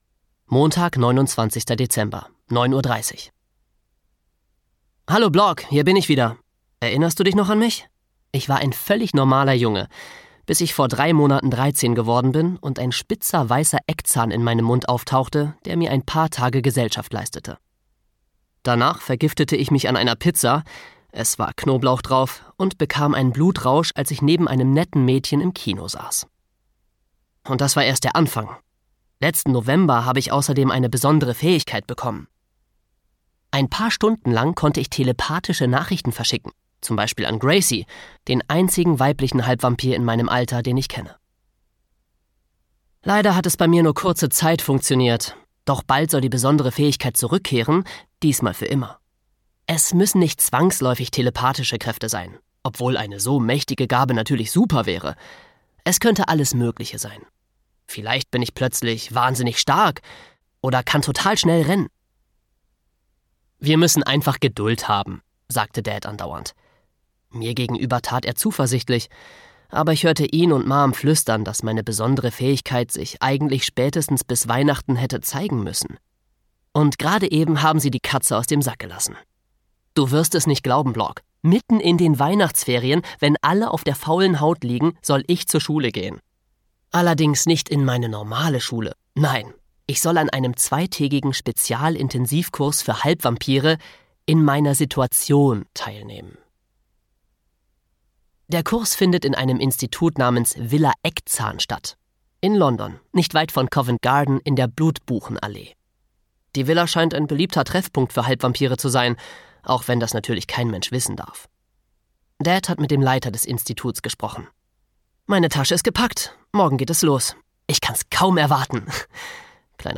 Wie man 13 wird und die Welt rettet (Wie man 13 wird 3) - Pete Johnson - Hörbuch